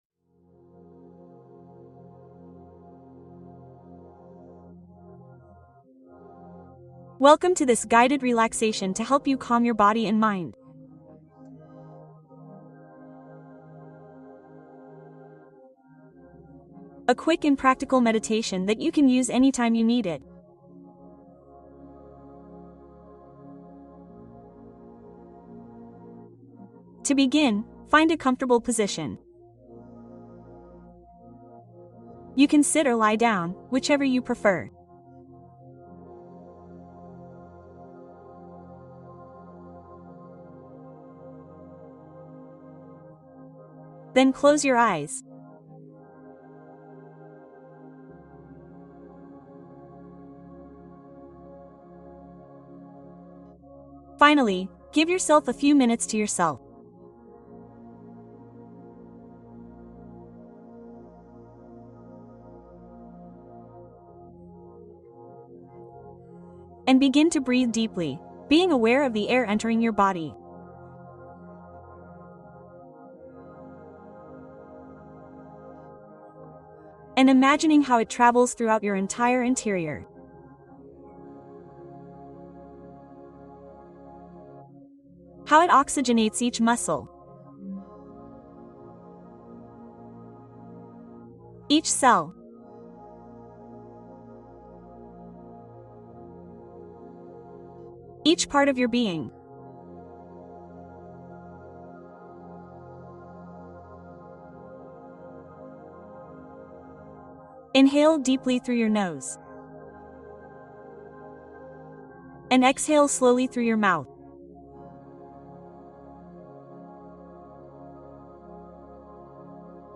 Escáner corporal | Relajación guiada para aliviar la ansiedad